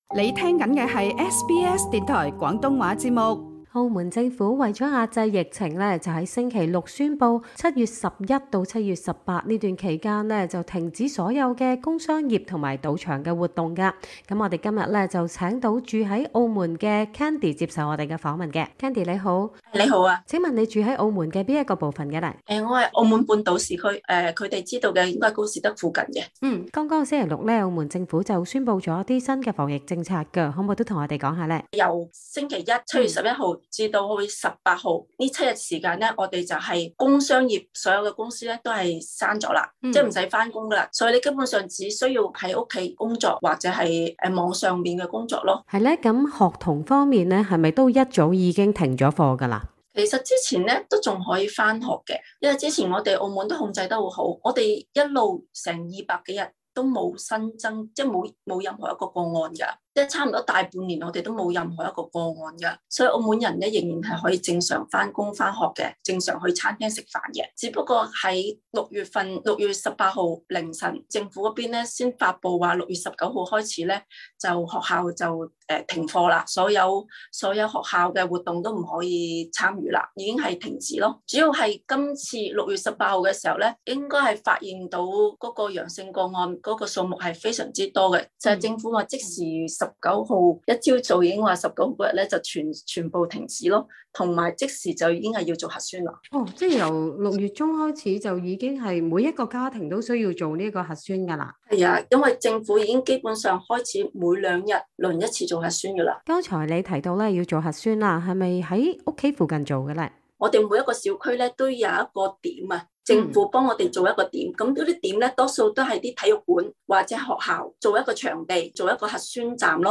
SBS廣東話訪問了當地居民了解最新發展。